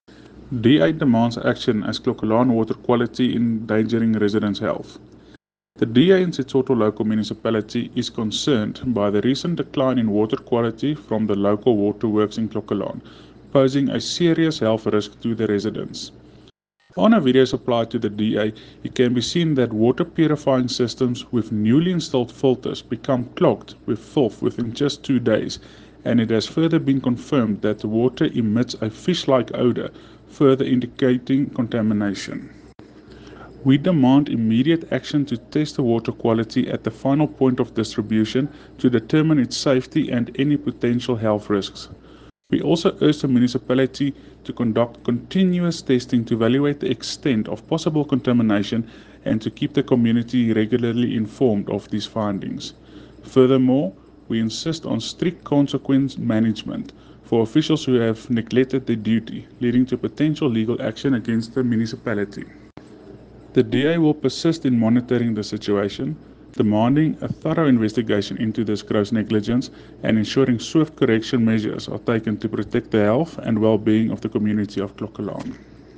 Issued by Jose Coetzee – DA Councillor Setsoto Local Municipality
Afrikaans soundbites by Cllr Jose Coetzee and